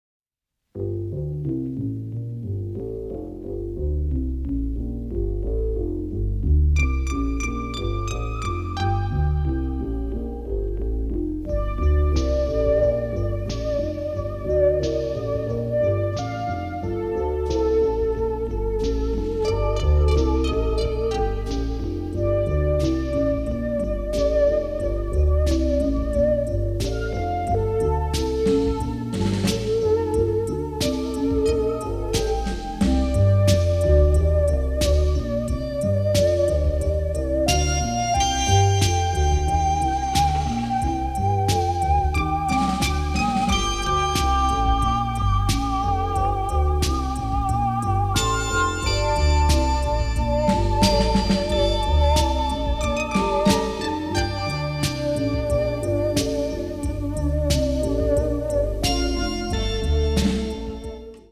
studio in the Los Angeles Hills
progressive rock and New Wave music